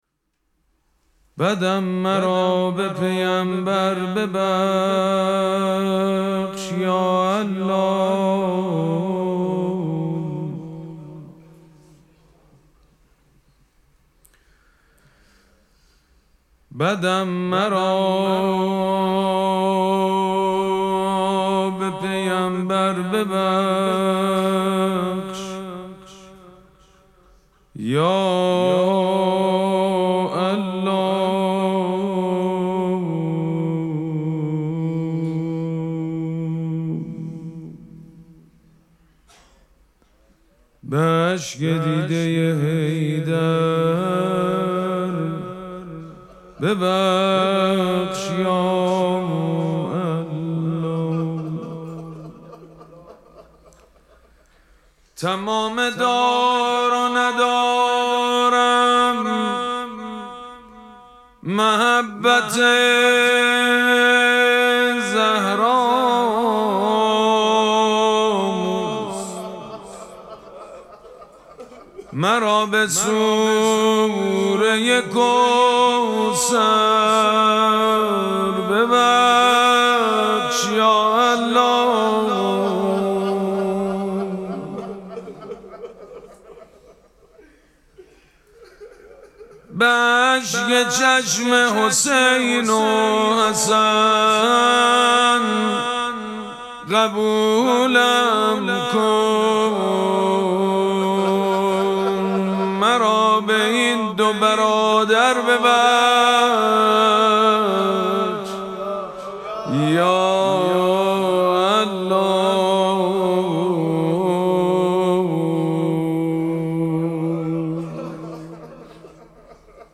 مراسم مناجات شب هجدهم ماه مبارک رمضان
مناجات
حاج سید مجید بنی فاطمه